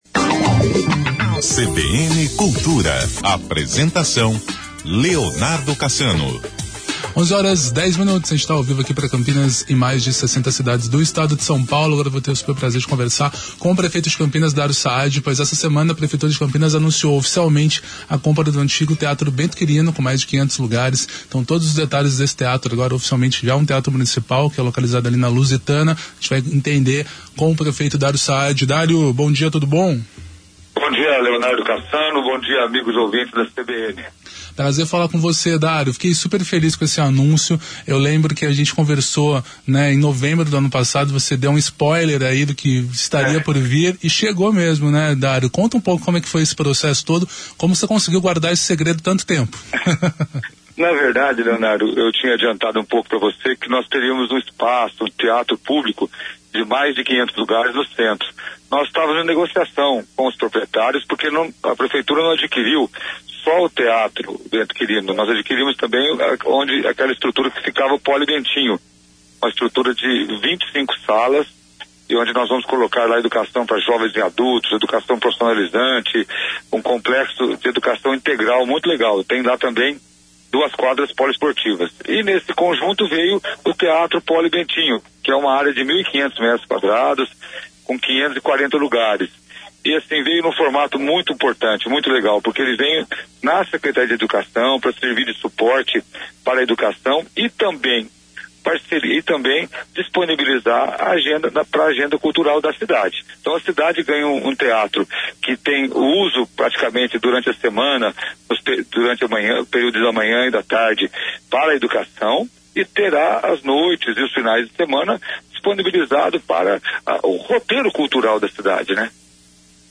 Entrevista Dário Saadi: Prefeito explica os detalhes do novo Teatro Municipal - CBN Campinas 99,1 FM
O prefeito Dário Saadi concedeu entrevista exclusiva no CBN Cultura, explicando todos os detalhes do novo Teatro Municipal de Campinas, o antigo Teatro Bento Quirino, além de comentar sobre outros assuntos da cultura da cidade.